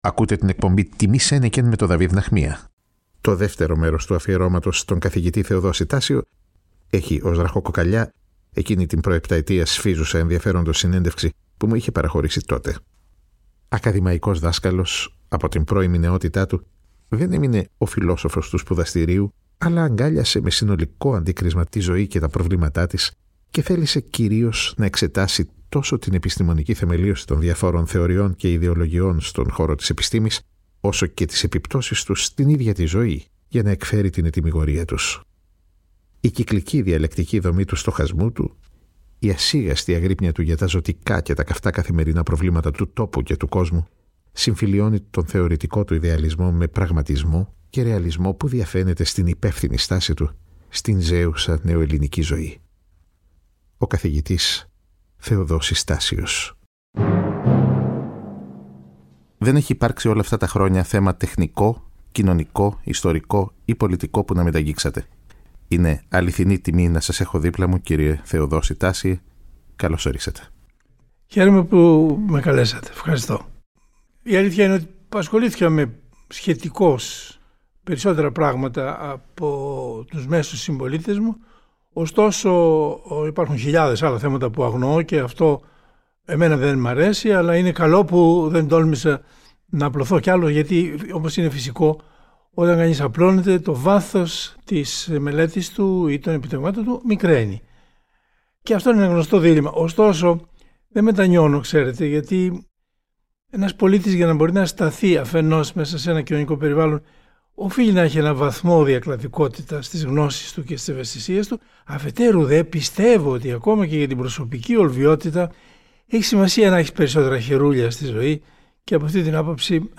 Στην εκπομπή αυτή ακούμε ξανά τον σπουδαίο Έλληνα διαννοούμενο σε μια παλαιότερη συνέντευξή του, από το 2017.